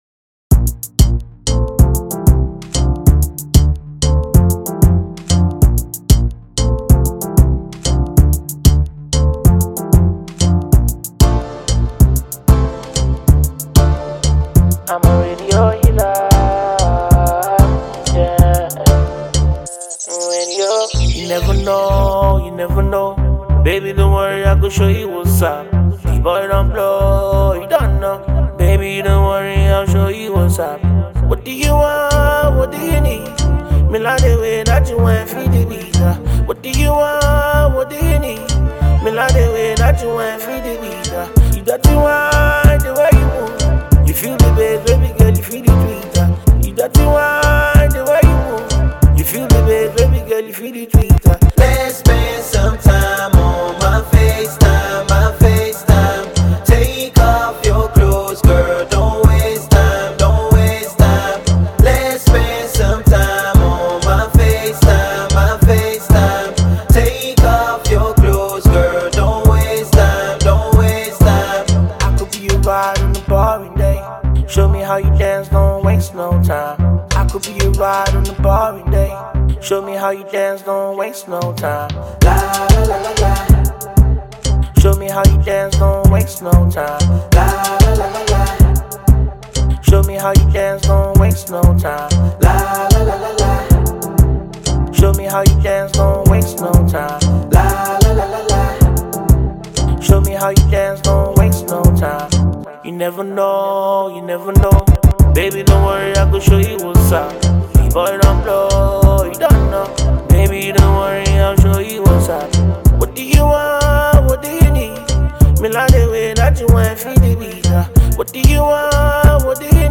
soft melody jam